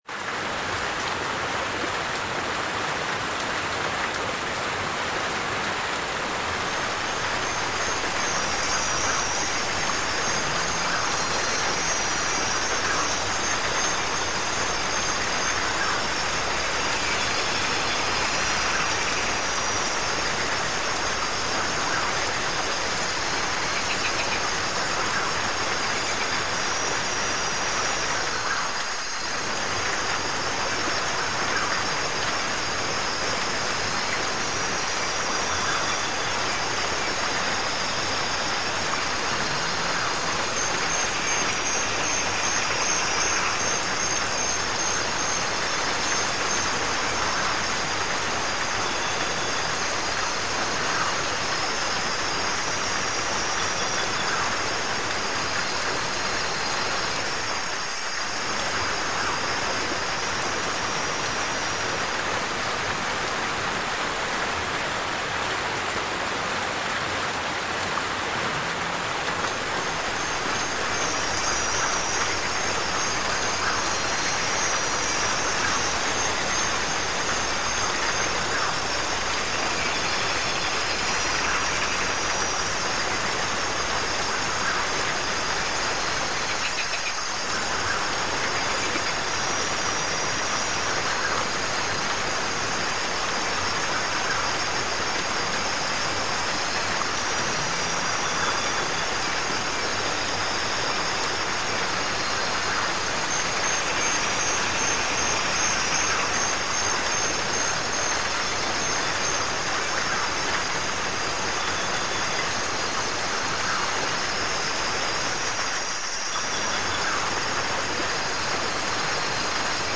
Música para relajarse, pensar y meditar.